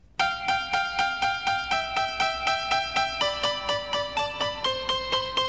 waltz.mp3